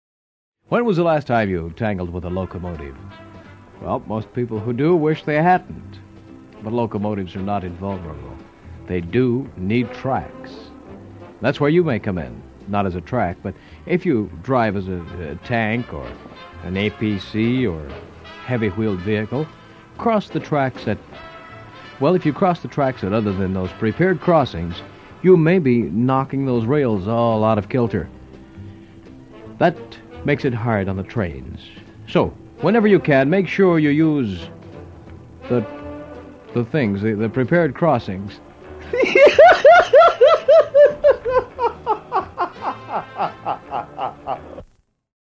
Tags: Armed Forces Radio Vietnam Bloopers AFVN Vietnam war Armed Forces Radio Vietnam Vietnam War Radio